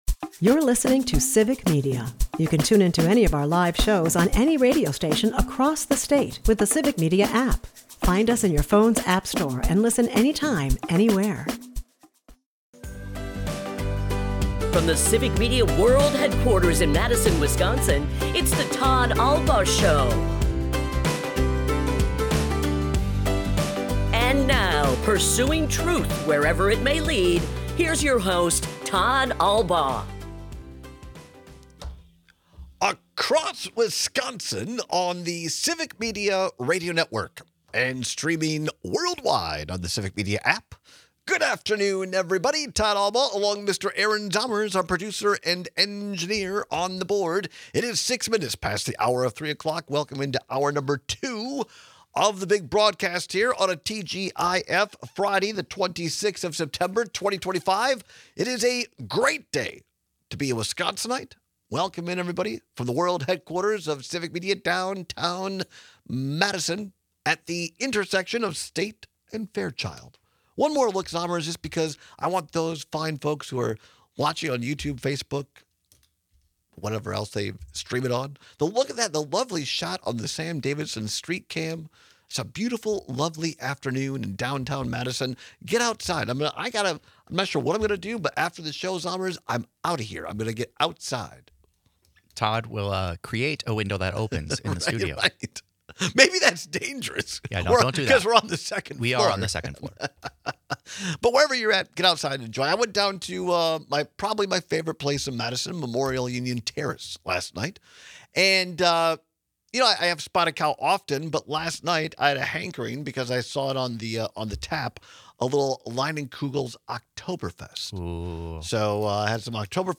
We take your calls and texts on which weakened beverage you’d rather drink.&nbsp